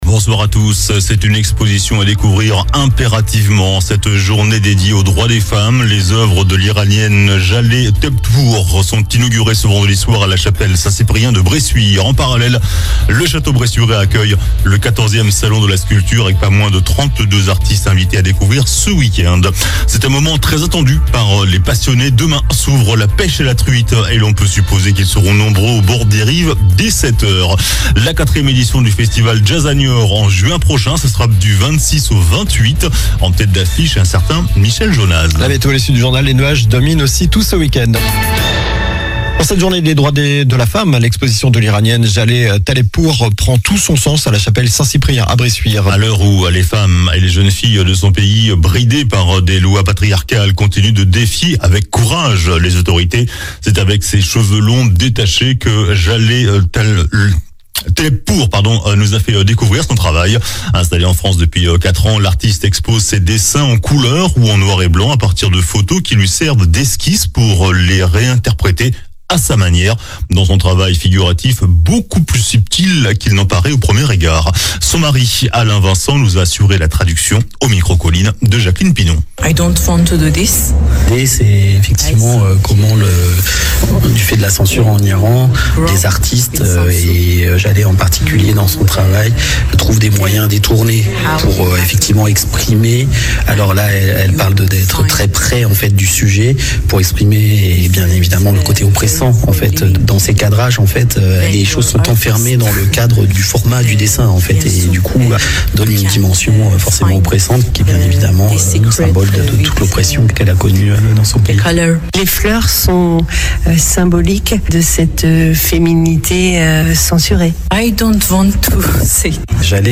JOURNAL DU VENDREDI 08 MARS ( SOIR )